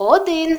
Added Ukrainian numbers voice files